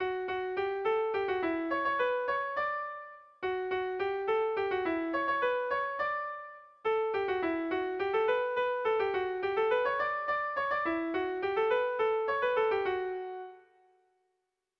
Air de bertsos - Voir fiche   Pour savoir plus sur cette section
Irrizkoa
Zortziko berezia, 5 puntuz (hg) / Bost puntuko berezia (ip)
AABDE